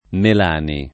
[ mel # ni ]